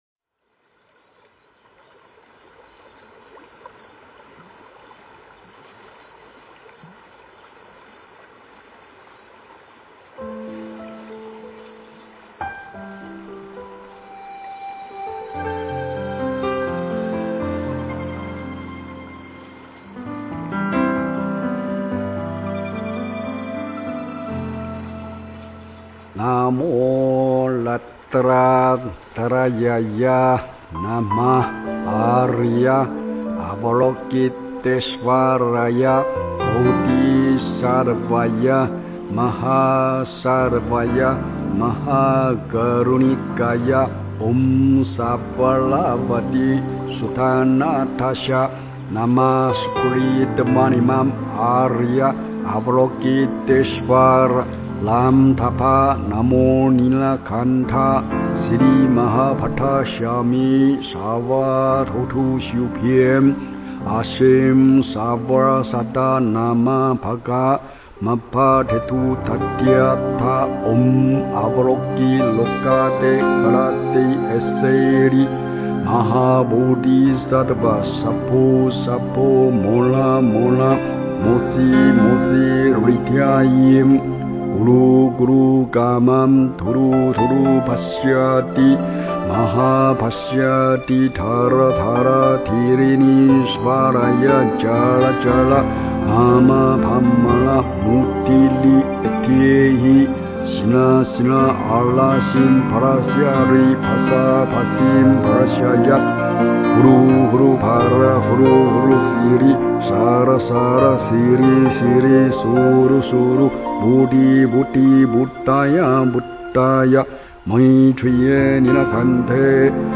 佛音 诵经 佛教音乐 返回列表 上一篇： 拜愿 下一篇： 观音圣号(闽南语合唱版